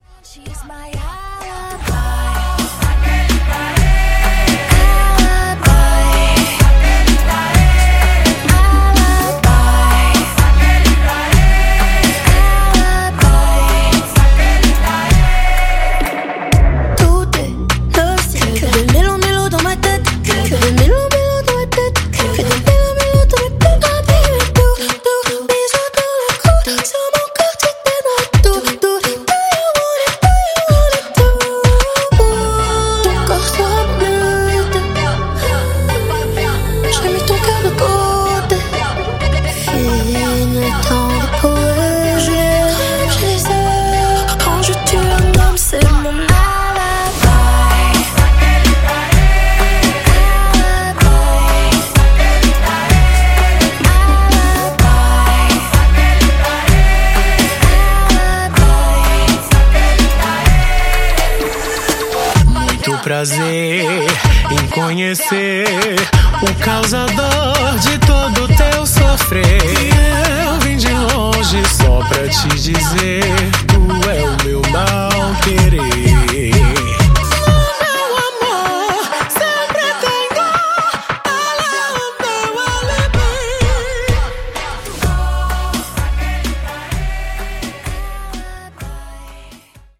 Genre: 80's
Clean BPM: 128 Time